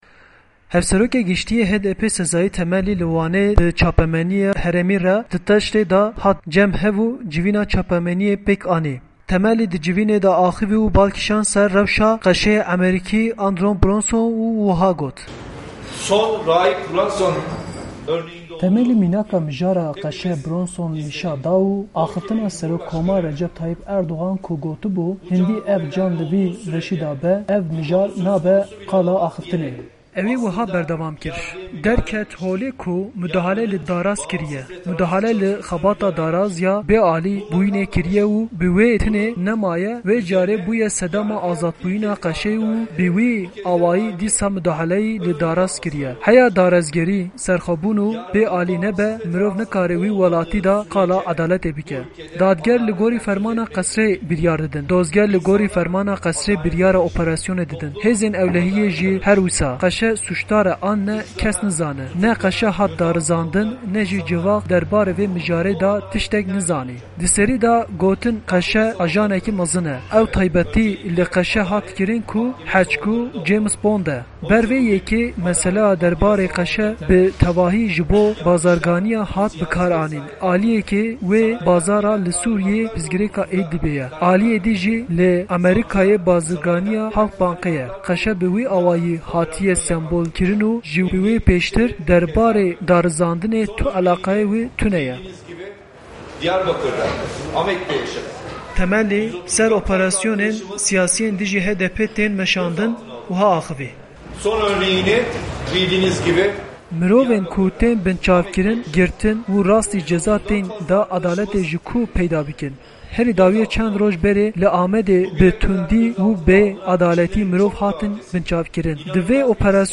Hevserokê Giştî yê HDP'ê Sezaî Temellî, li Wanê di taştîyê de bi medya herêmî re kom bû û dû re, civîna çapemenî pêk anî.